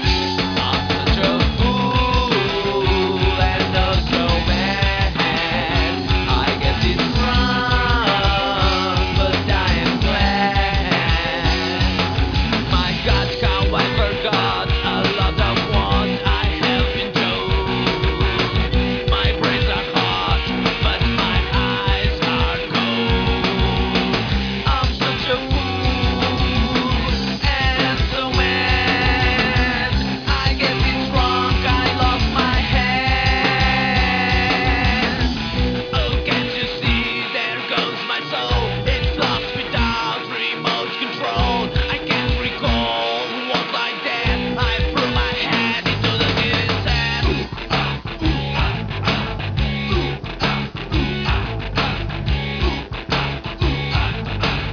guitar
bass
drums, vocals
violin, vocals
recorded in Studio Tivoli, 1992